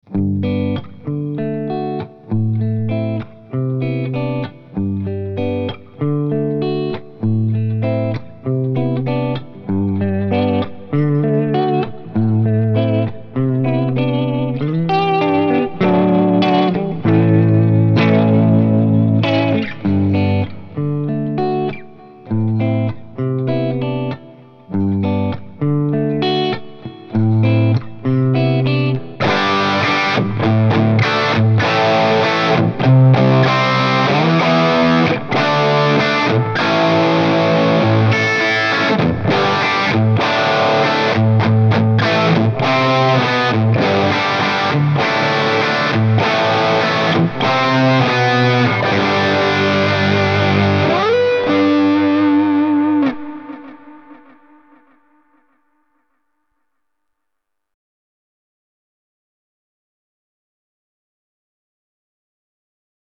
This Amp Clone rig pack is made from a Fuchs ODS 50 amp.
IR USED: MARSHALL 1960A V30 SM57+ E906 POS 1
RAW AUDIO CLIPS ONLY, NO POST-PROCESSING EFFECTS